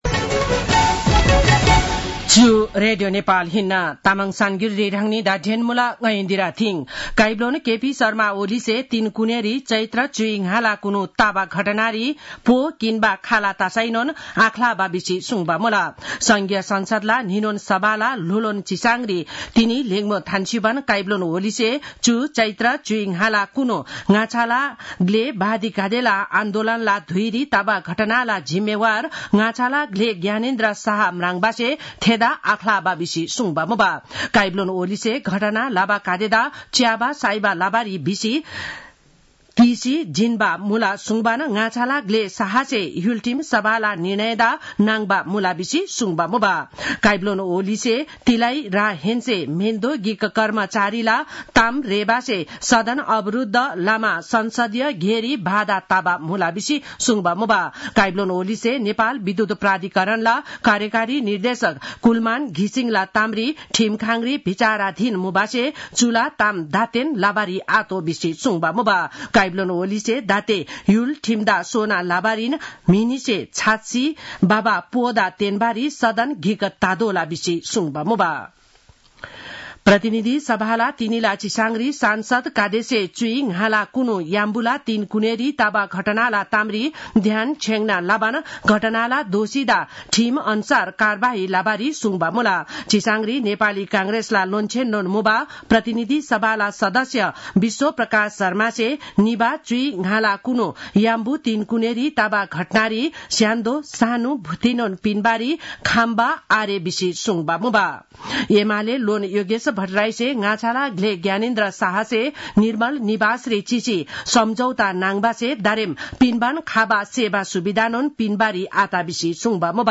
तामाङ भाषाको समाचार : १८ चैत , २०८१